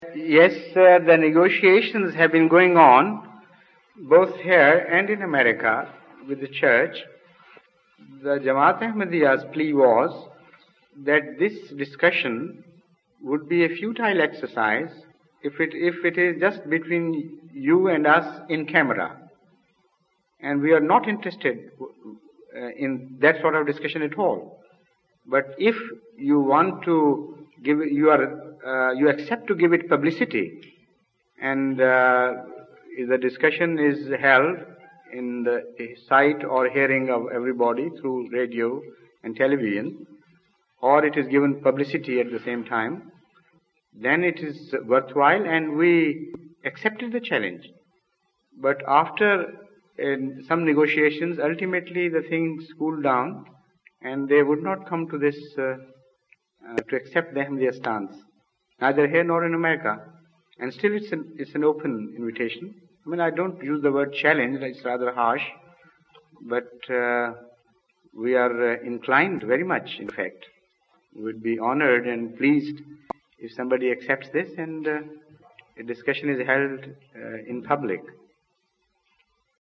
The London Mosque